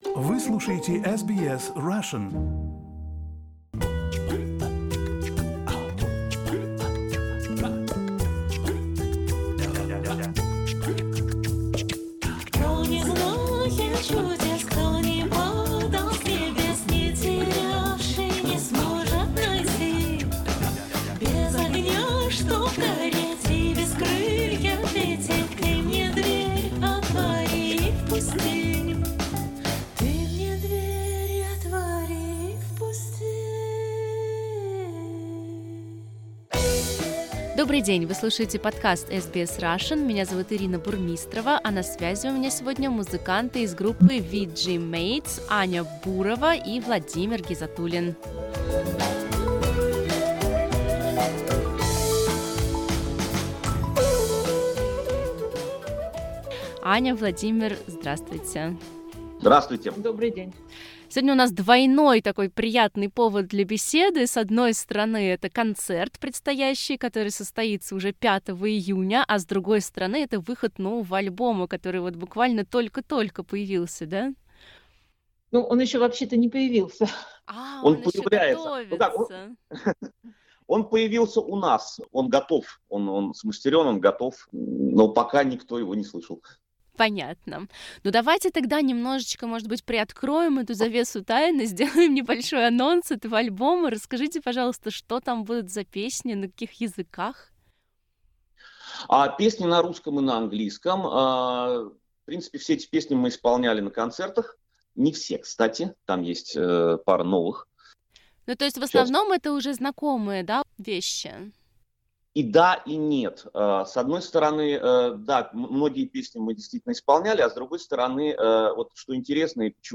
Мы поговорили об альбоме, любви к варгану, горловом пении и акценте. В подкасте звучат фрагменты песен из нового альбома.